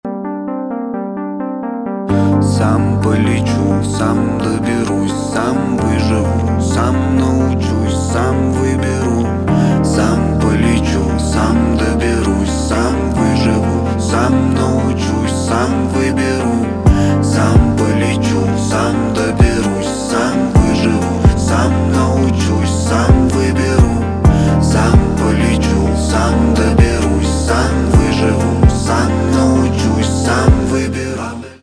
инди
пианино